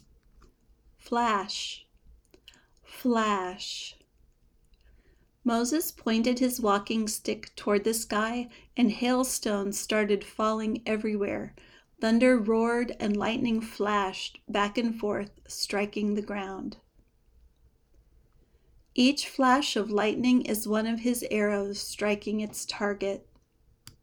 flæʃ (verb, also a noun)